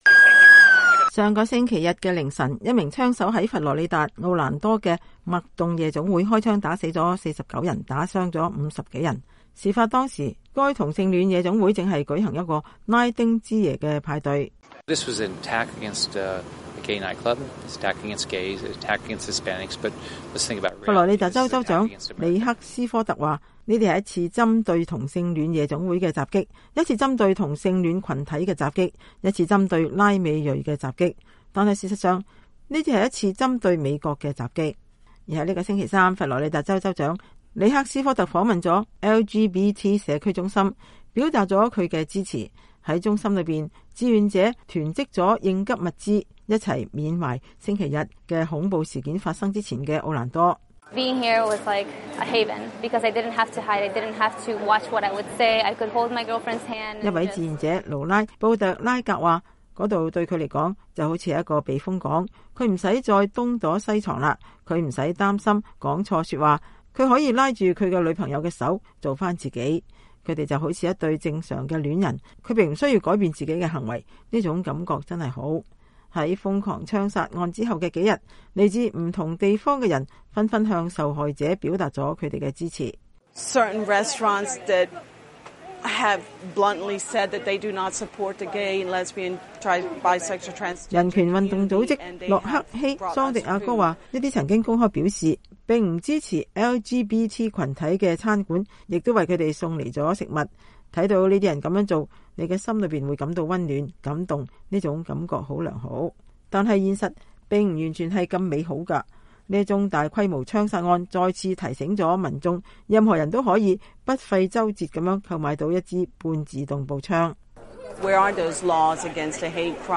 本周三，美國之音記者采訪了這場恐怖襲擊的倖存者。